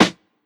Dirty Snare.wav